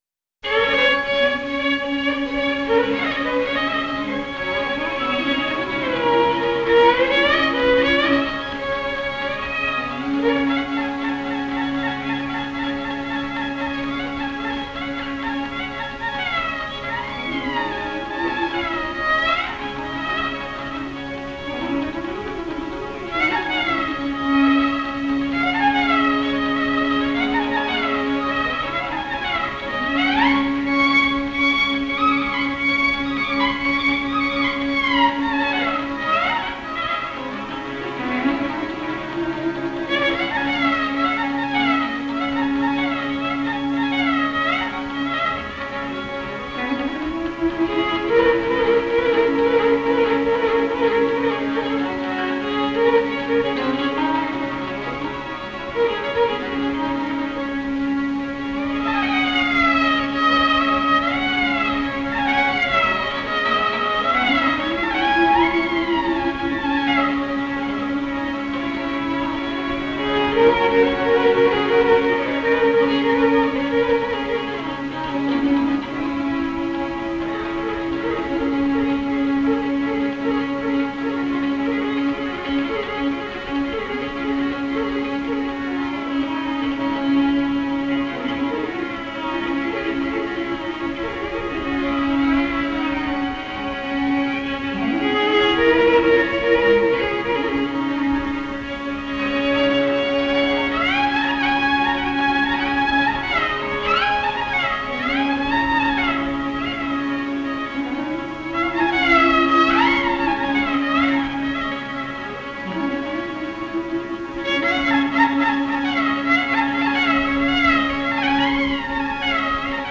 Kaba dy pjesëshe e tipit vajtimor: kaba dhe valle.
Bie në sy një aftësi e rrallë e lojës në violinë e cila shpesh herë përngjason me një vajtim me gojë. Karakteristike është se rolin e prerjes nuk e kryen gërneta por një tjetër violinë. Vallja e shtruar në ritëm dy pjesëtues është tipike për vallet e vajzave dhe grave përmetare e cila bashkëshoqërohet edhe nga gërneta, tipike për sazet e Jugut.